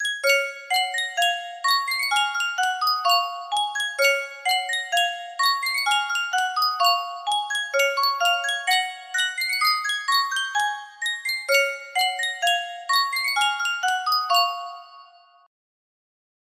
Sankyo Music Box - Bluebells of Scotland TS music box melody
Full range 60